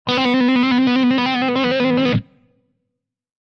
descargar sonido mp3 guitarra